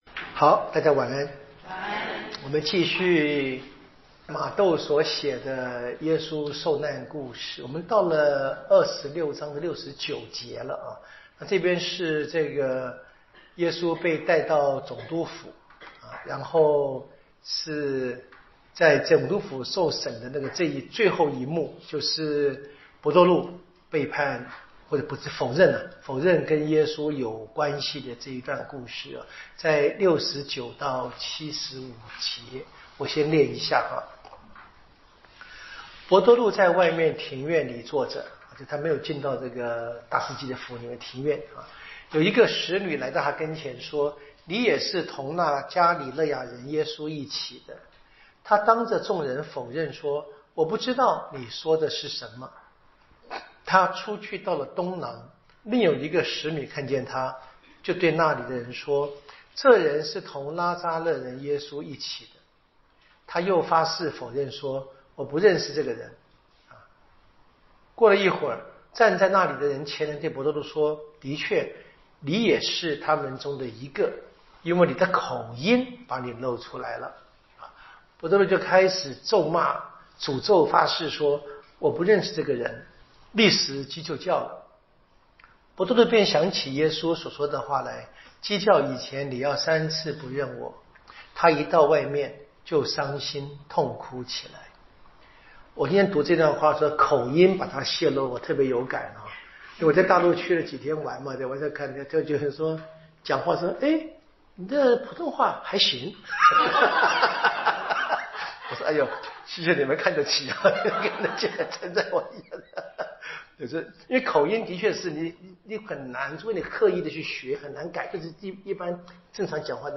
【圣经讲座】《玛窦福音》